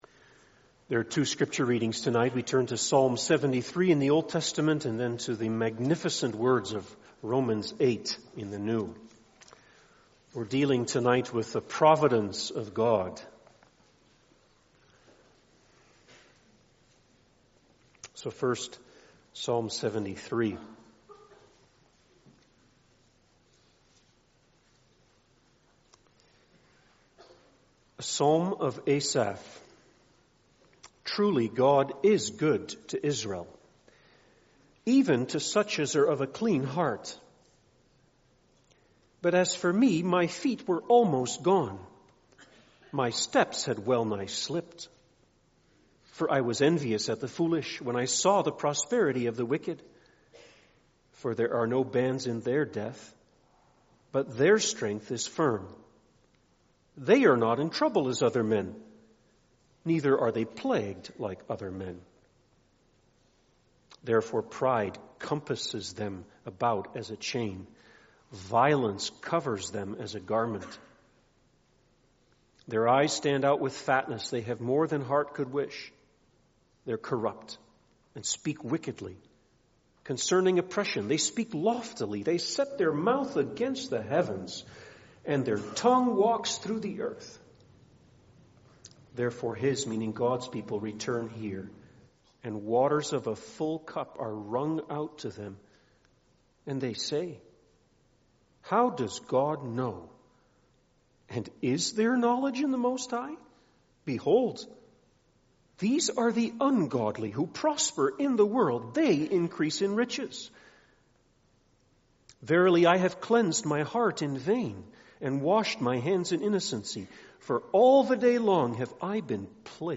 God's fatherly hand | SermonAudio Broadcaster is Live View the Live Stream Share this sermon Disabled by adblocker Copy URL Copied!